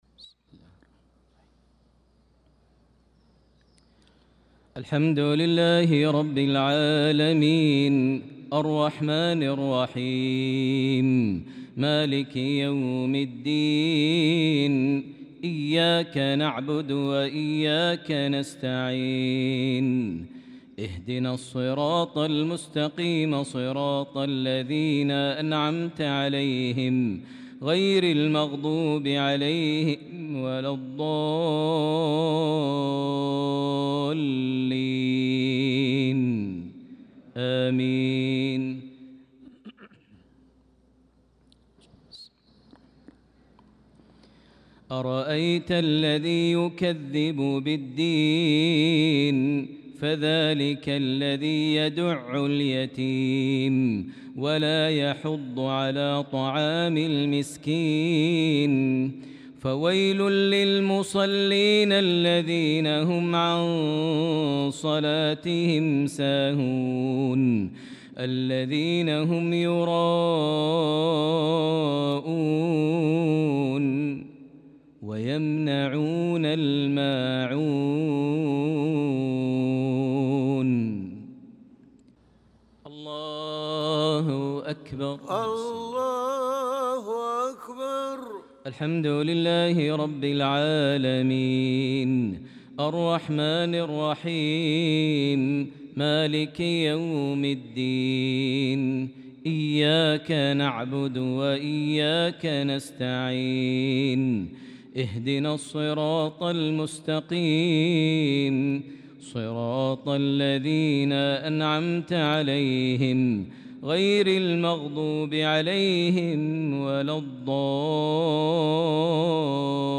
صلاة المغرب للقارئ ماهر المعيقلي 21 شوال 1445 هـ
تِلَاوَات الْحَرَمَيْن .